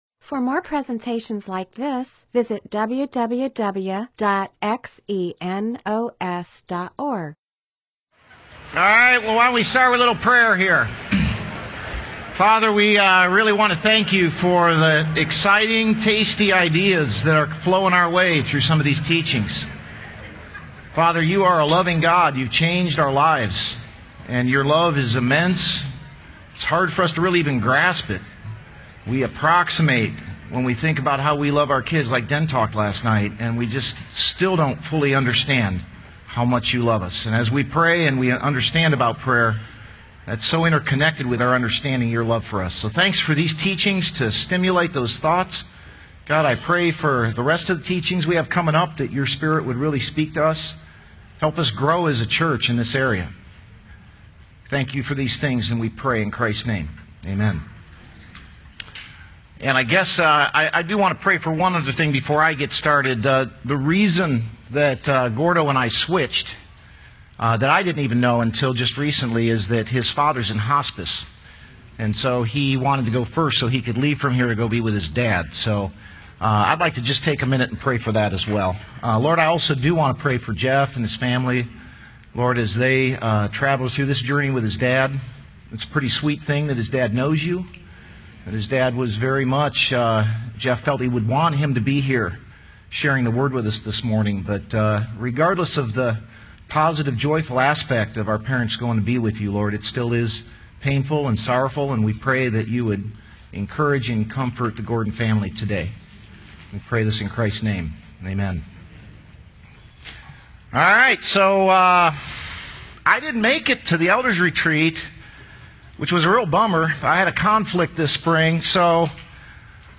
MP4/M4A audio recording of a Bible teaching/sermon/presentation about Acts 6:1-4.